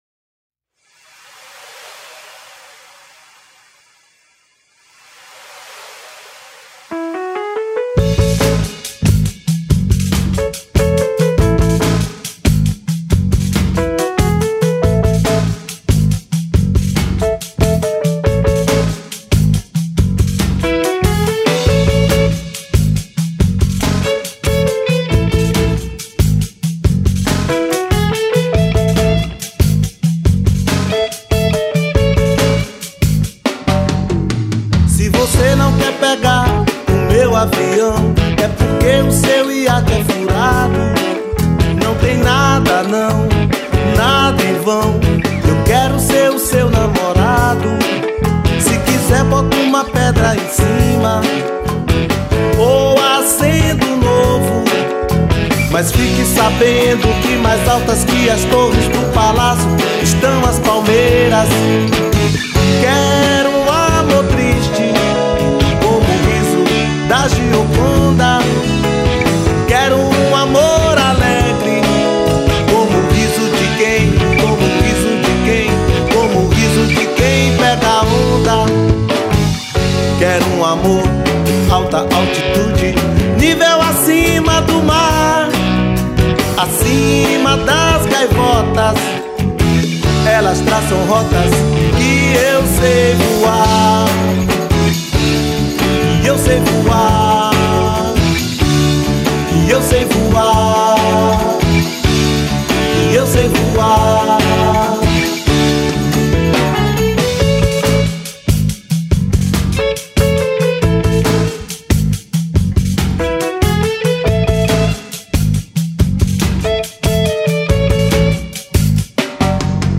1338   04:08:00   Faixa:     Rock Nacional